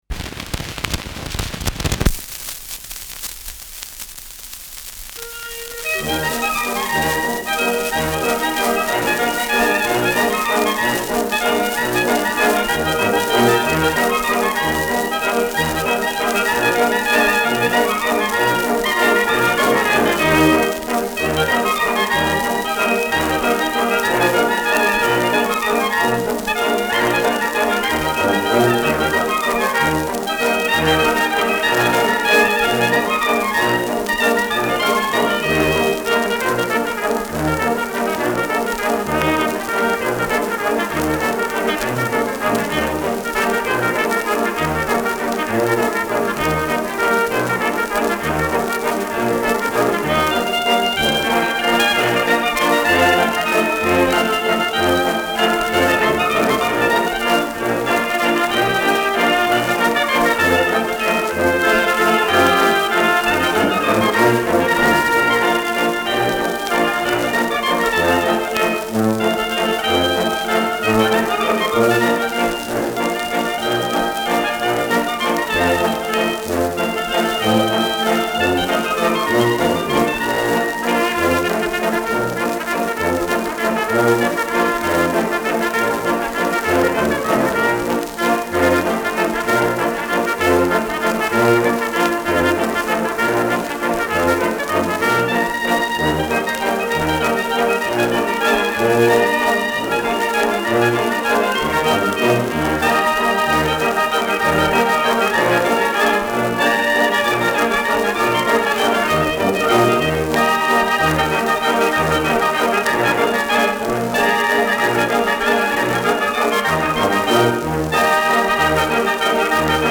Schellackplatte
Leichtes Leiern : Vereinzelt leichtes Knacken
[München] (Aufnahmeort)